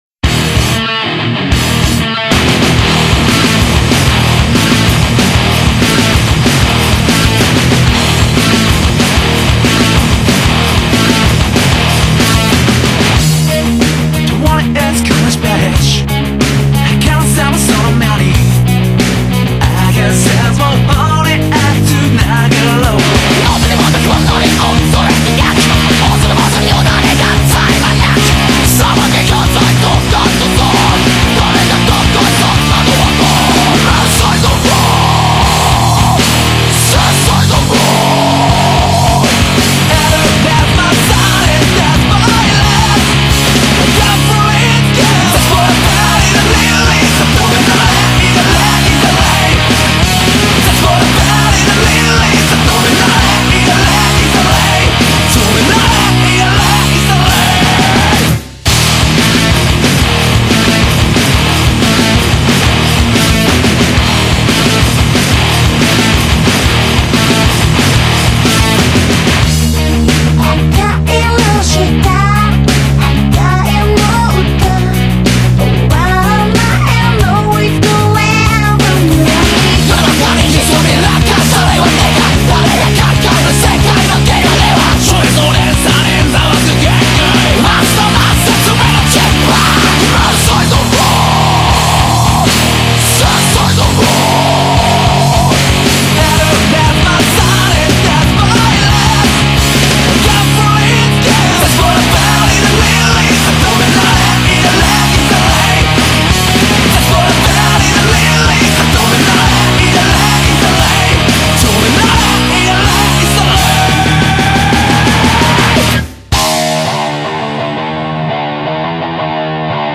Второй эндинг, полная версия, J-rock, альтернатива.